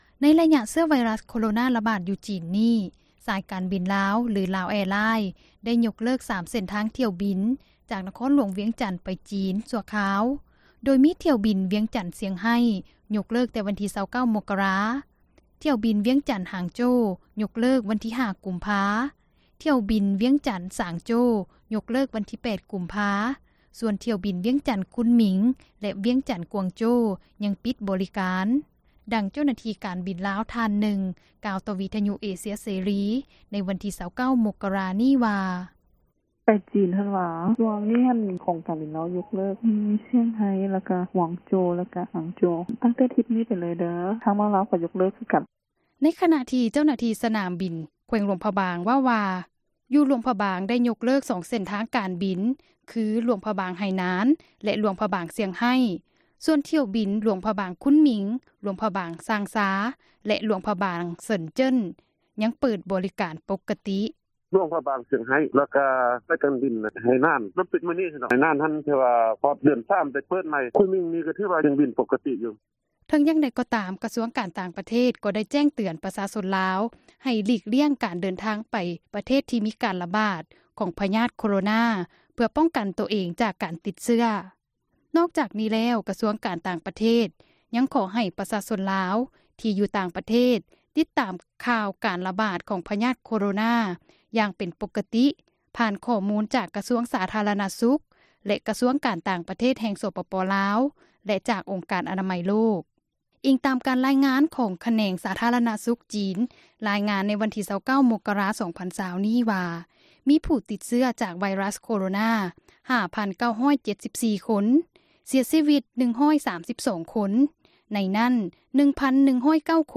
ໂດຍມີຖ້ຽວບິນ ວຽງຈັນ-ຊຽງໄຮ ໂຈະແຕ່ວັນທີ 29 ມົກກະຣາ, ຖ້ຽວບິນ ວຽງຈັນ-ຫາງໂຈ ໂຈະວັນທີ 5 ກຸມພາ, ຖ້ຽວບິນວຽງຈັນ-ສາງໂຈ ໂຈະວັນທີ 8 ກຸມພາ ສ່ວນຖ້ຽວບິນ ວຽງຈັນ-ຄຸນໝິງ ແລະ ວຽງຈັນ-ກວາງໂຈ ຍັງປິດໃຫ້ບໍຣິການ, ດັ່ງເຈົ້າໜ້າທີ່ ການບິນລາວ ທ່ານນຶ່ງ ກ່າວຕໍ່ວິທຍຸ ເອເຊັຽເສຣີ ໃນວັນທີ 29 ມົກກະຣາ ນີ້ວ່າ: